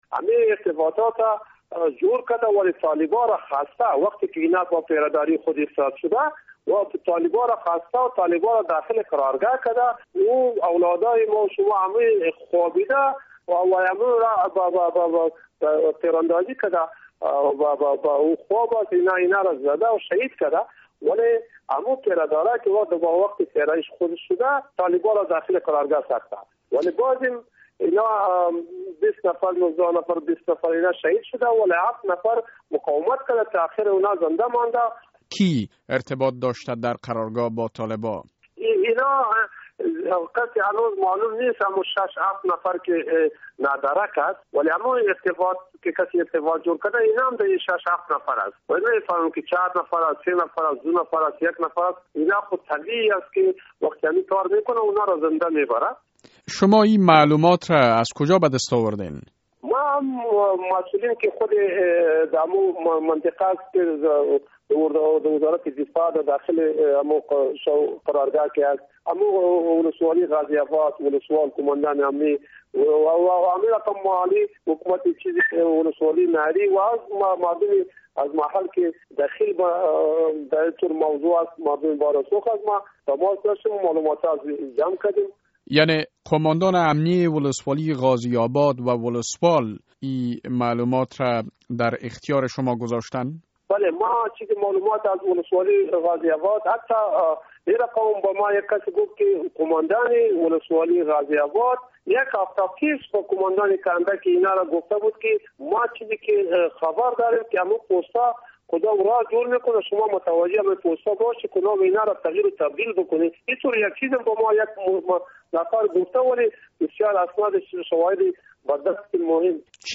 مصاحبه با صالح محمد صالح در مورد کشته شدن عساکر اردو در کنر